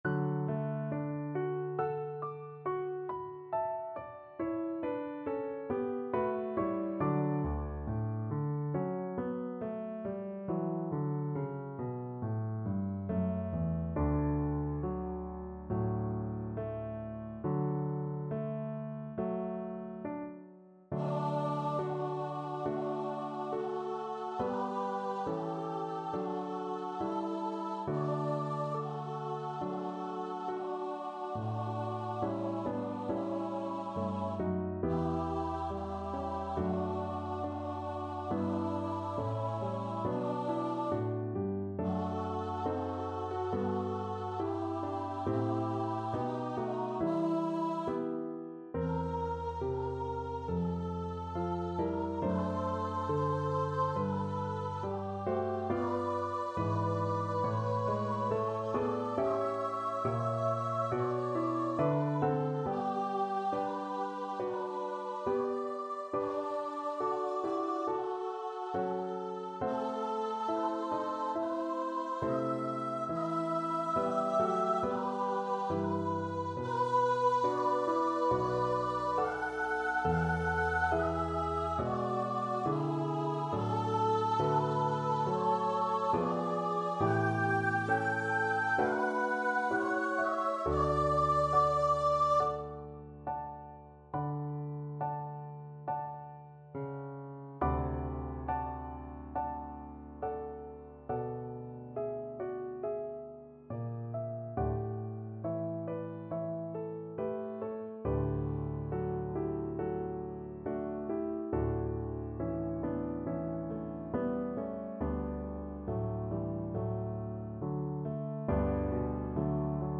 Free Sheet music for Vocal Duet
SopranoAlto
D major (Sounding Pitch) (View more D major Music for Vocal Duet )
Slow =c.69
2/2 (View more 2/2 Music)
Classical (View more Classical Vocal Duet Music)